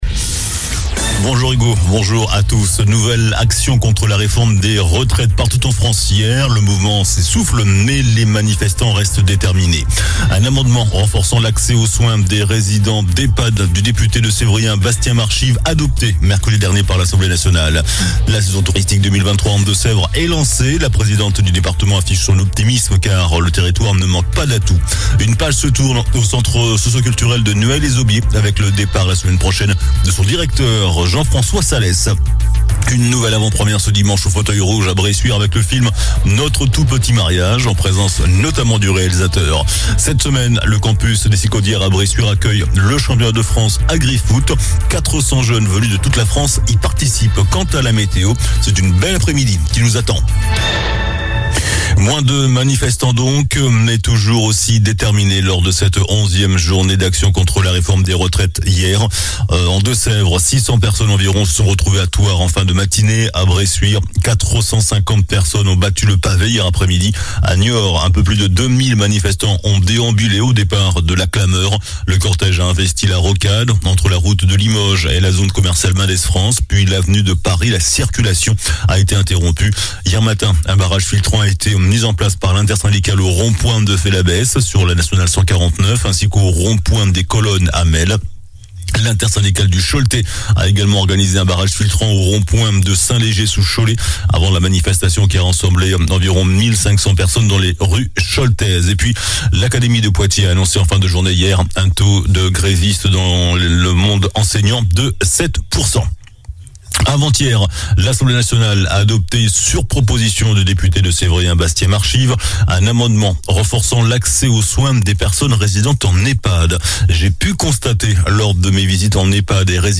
JOURNAL DU VENDREDI 07 AVRIL ( MIDI )